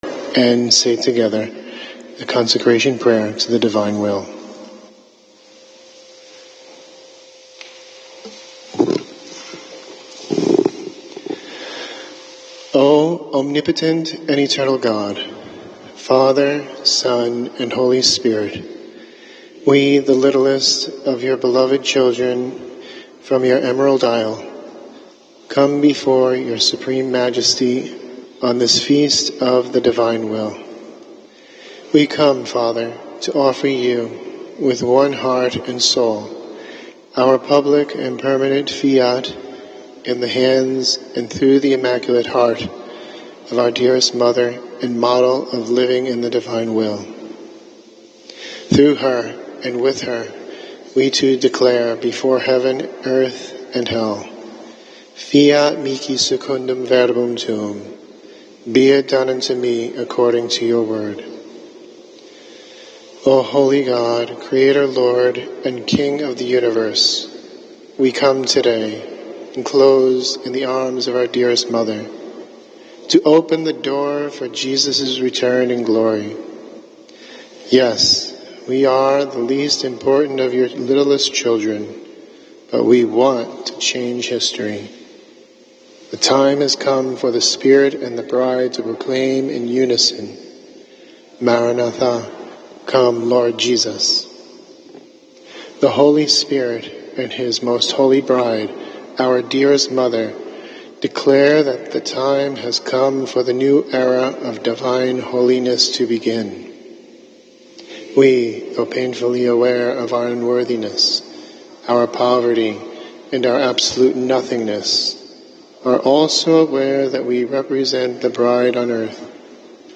praying Consecration Prayer